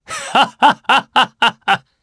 Roman-Vox_Happy2_jp.wav